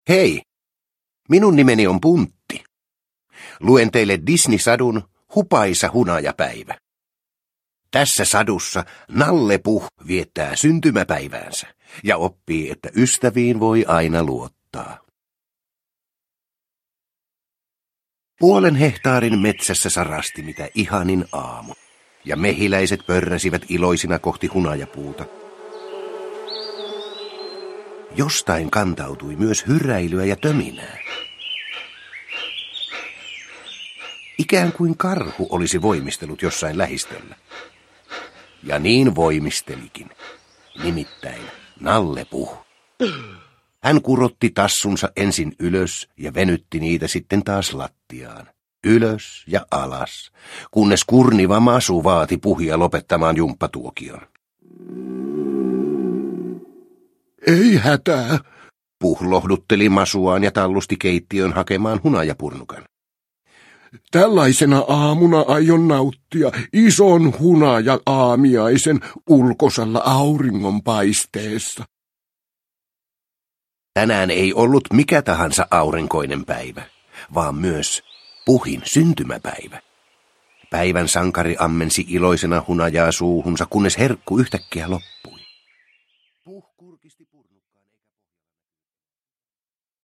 Nalle Puh. Hupaisa hunajapäivä – Ljudbok – Laddas ner